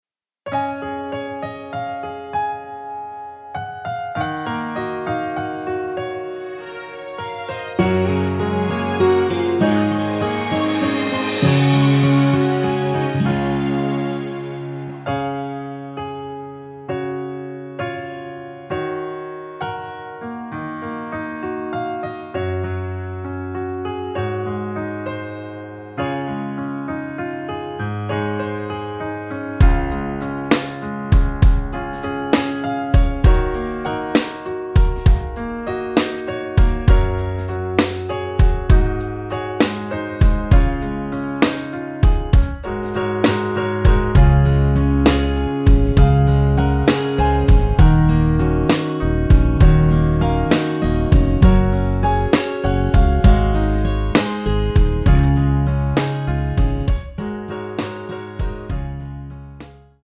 원키에서(-7) 내린 MR 입니다.(미리듣기 참조)
Db
◈ 곡명 옆 (-1)은 반음 내림, (+1)은 반음 올림 입니다.
앞부분30초, 뒷부분30초씩 편집해서 올려 드리고 있습니다.
중간에 음이 끈어지고 다시 나오는 이유는